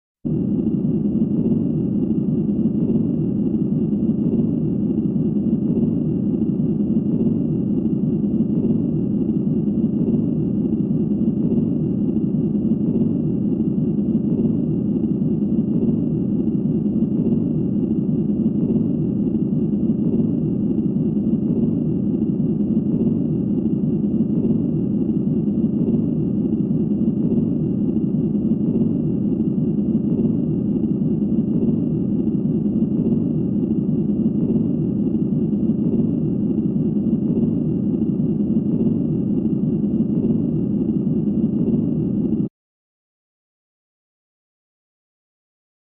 Synth Spaceship Interior 1; Reverberant, Low Frequency Rumble.